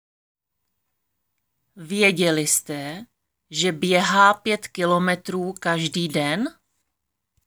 Tady si můžete stáhnout audio na výslovnost VĚ, BĚ, PĚ: Věděli jste, že běhá pět kilometrů každý den.